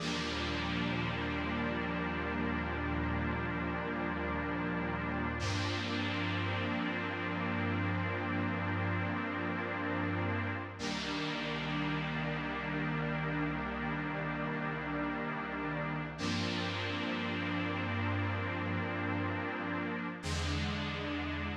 03 pad C.wav